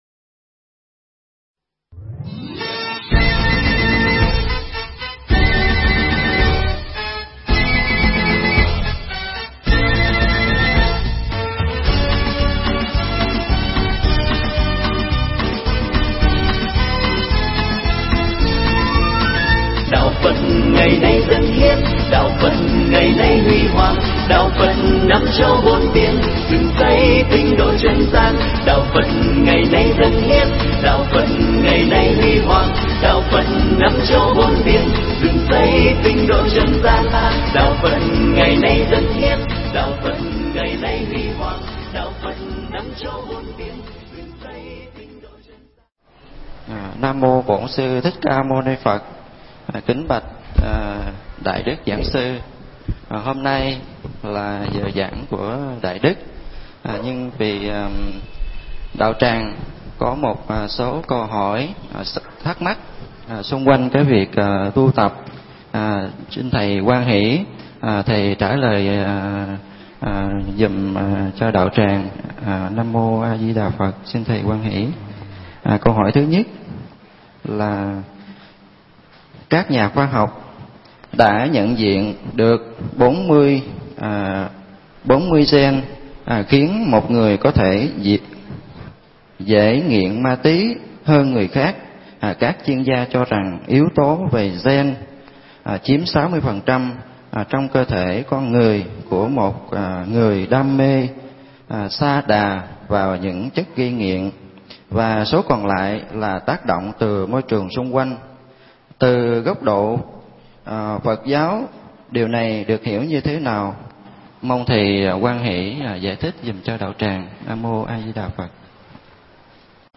Phật giáo và các vấn đề xã hội – thầy Thích Nhật Từ thuyết pháp mp3
Nghe mp3 pháp thoại Phật giáo và các vấn đề xã hội được thầy Thích Nhật Từ giảng tại Chùa Ấn Quang, ngày 23 tháng 11 năm 2008